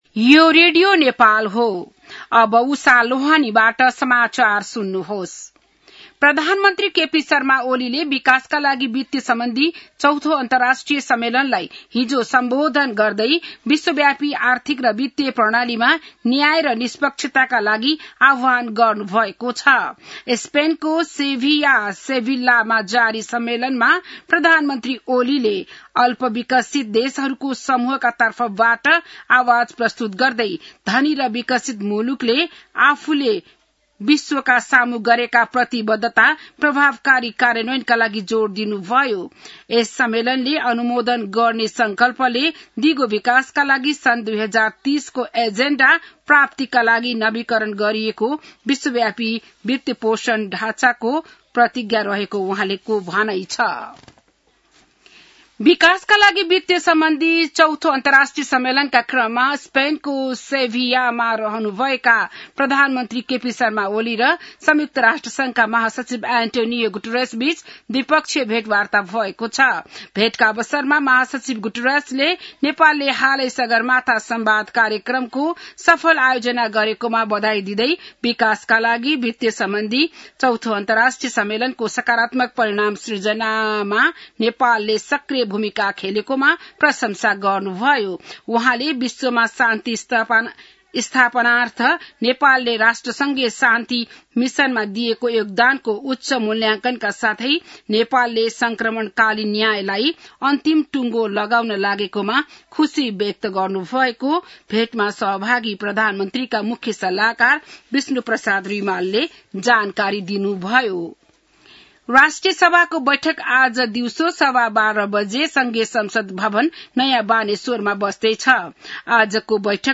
बिहान १० बजेको नेपाली समाचार : १७ असार , २०८२